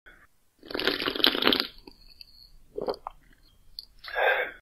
Звуки питья в Майнкрафт весьма специфичны и совсем не похожи на реальные звуки.
Отпил, проглотил и сказал “Аа”
Roblox-Drinking-Sound-Effect-256-kbps.mp3